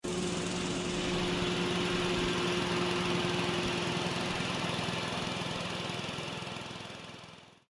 发电机关机
Tag: 发电机 静电 故障 关掉 功率 电力 掉电